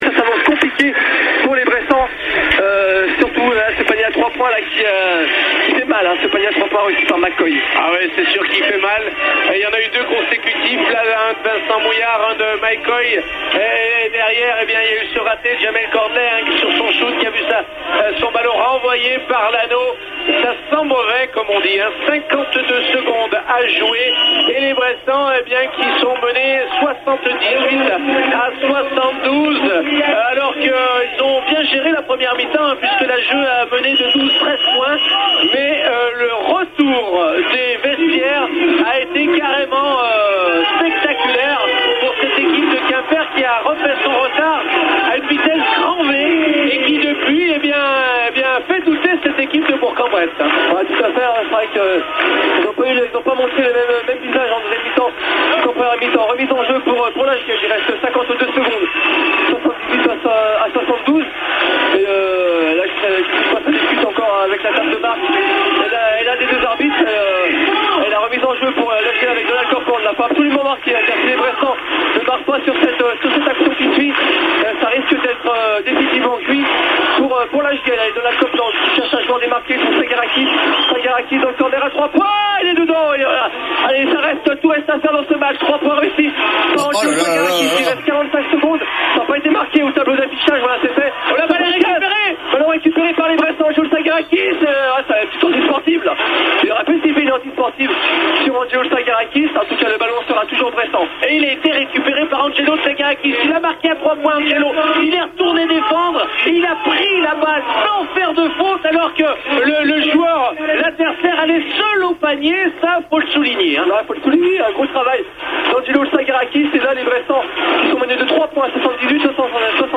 Les 52 dernières secondes du match, suivi des réactions d'après-match.
fin_de_match_quimper_les_52s_sons.mp3